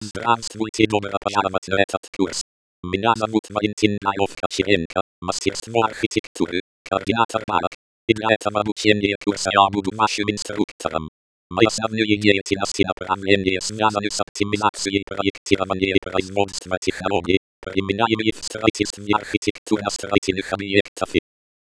Режим: Видео + озвучка + субтитры (Русский)
аудио TTS (WAV)